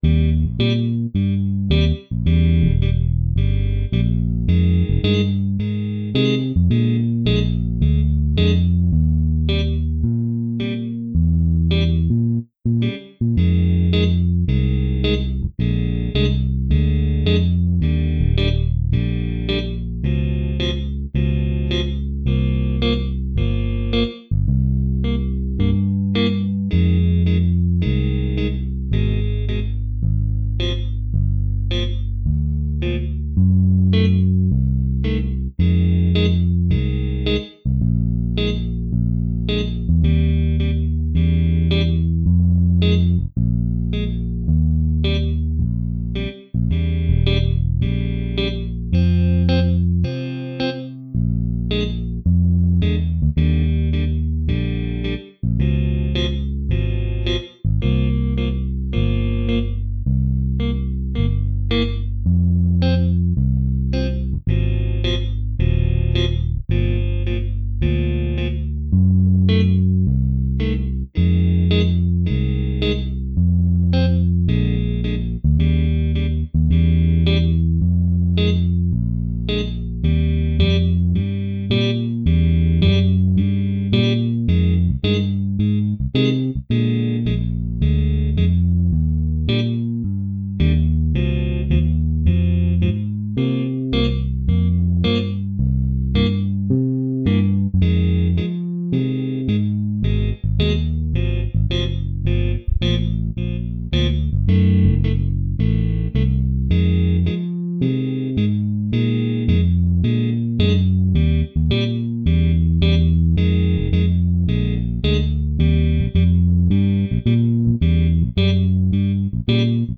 Naked      (pickup end of 2nd bar)